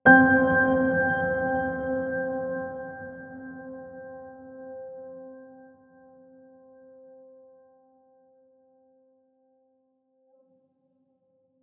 piano3.wav